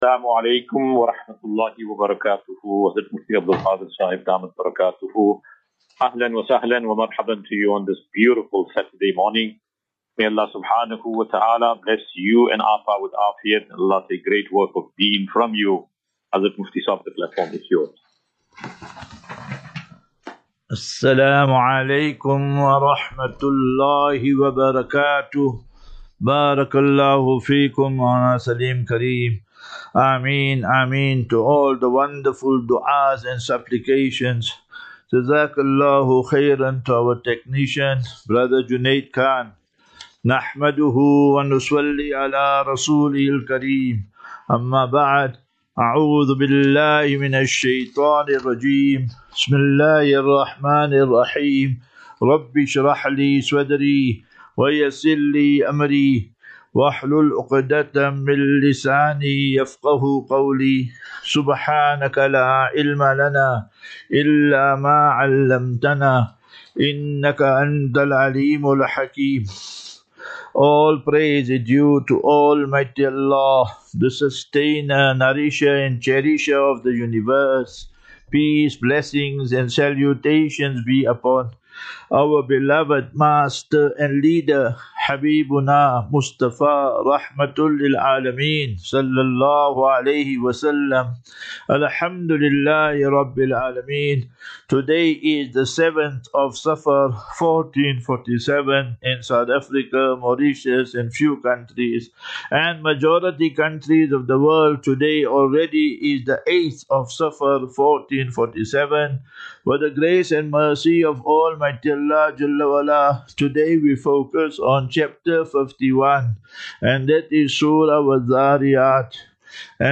2 Aug 02 August 2025. Assafinatu - Illal - Jannah. QnA.
Daily Naseeha.